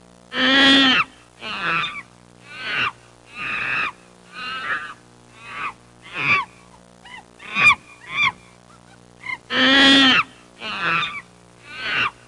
One Annoying Monkey Sound Effect
one-annoying-monkey.mp3